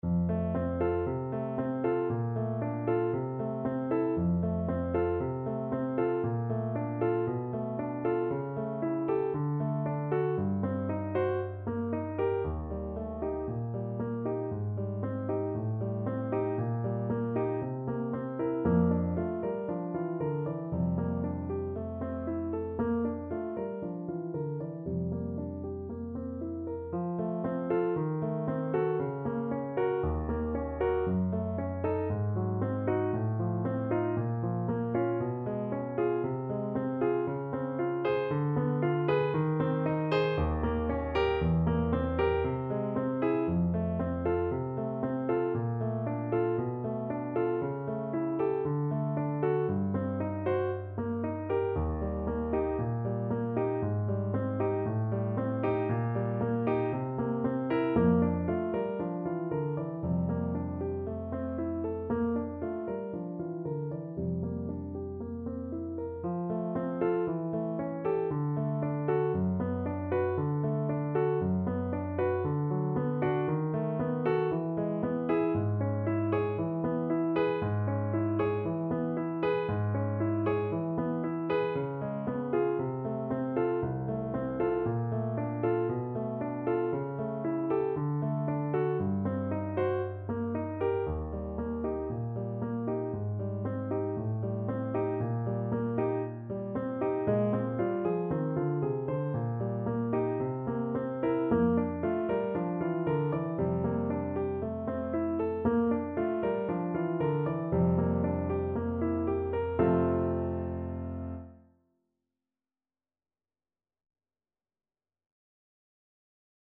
Allegro moderato =116 (View more music marked Allegro)